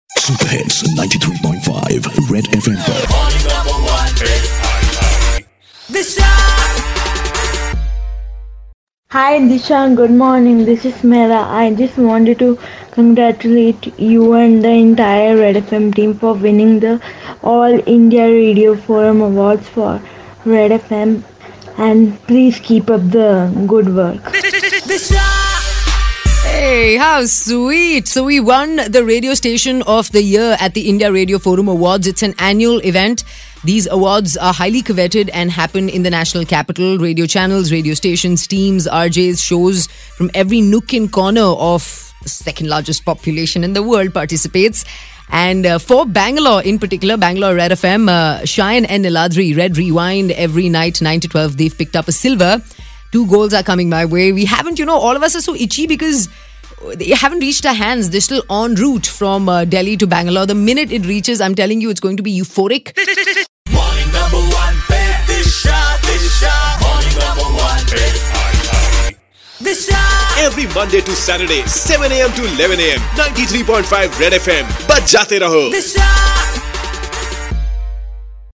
one of the listener sends a voicenote